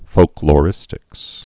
(fōklô-rĭstĭks, -lō-)